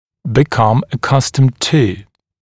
[bɪ’kʌm ə’kʌstəmd tuː][би’кам э’кастэмд туː]привыкать к